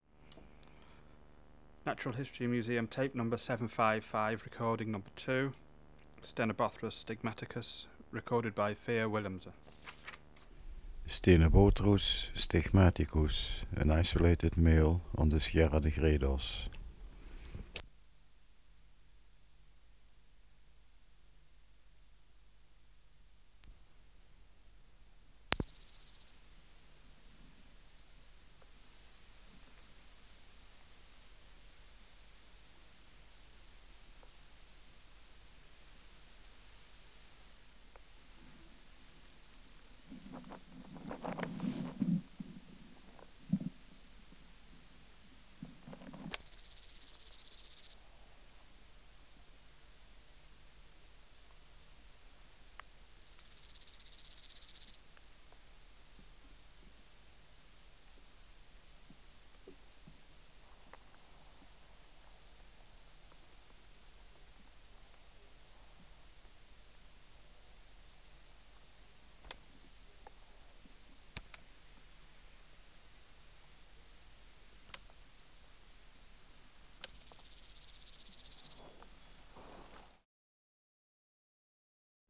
587:2 Stenobothrus stigmaticus (755r2) | BioAcoustica
Microphone & Power Supply: AKG D202E (LF circuit off) Distance from Subject (cm): 8 Windshield: On base & top
Recorder: Uher 4200
Tape: BASF LP35LH Tape Speed (cm/s): 19.00